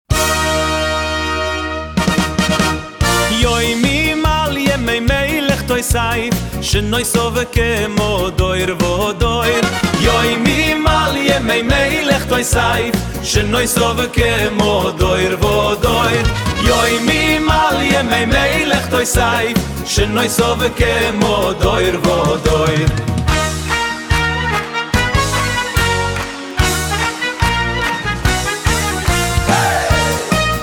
נכתב ע"י [[ {{ • }} ]]: תנסה את זה: קוד: ז'אנר: פופ חסידי-ליטאי אנרגטי (Simcha Wedding Style) ועיבוד תזמורתי חגיגי.
מלודיה מובילה: סקציית כלי נשיפה ממתכת (Brass Section) עוצמתית – חצוצרות וטרומבונים בתרועות מלכותיות (Fanfare).
שירה והגייה: דמות קולית: זמר חסידי גברי עם קול עוצמתי וחגיגי (Powerhouse Cantorial Pop).
נסיתי, צריך להשתפר בהגיה ורק 30 שניות ואני מנוי...
תשמע את הכניסה של ה'מקהלה' ב- 0:09 ו- 0:17, ואת הסיום של זה ב- 0:22 את ההרמוניה שלהם כמה טונים מעל בדיוק בקטעים הספציפיים תוך חזרה מושלמת לטון המקורי ברגע הנכון ואת ההתאמה של כל הכלים ברקע, זה הזיה.